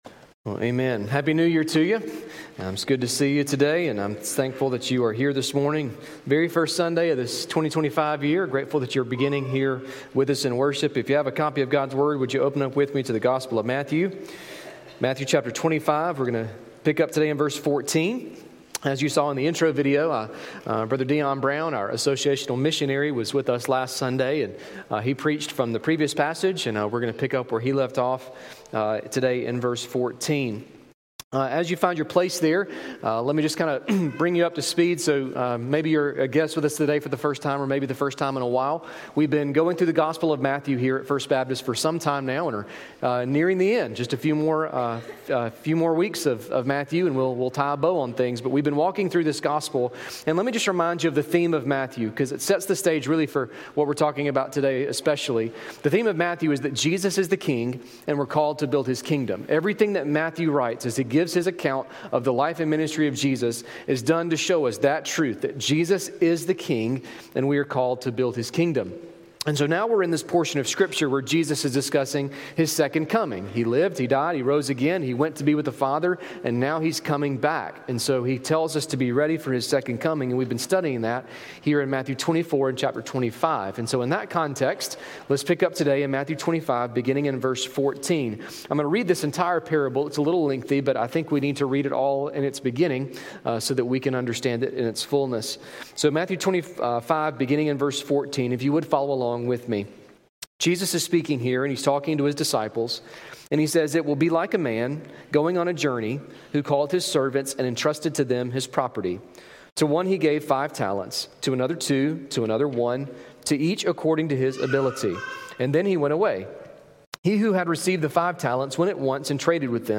A message from the series "Other."